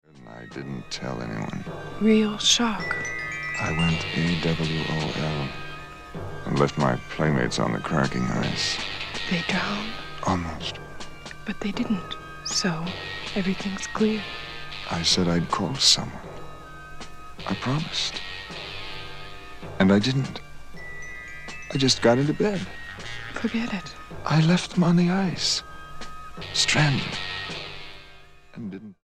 STYLE: Jesus Music
baritone speaking voice
fuzz guitars, groovy sounds and a sprinkling of avant garde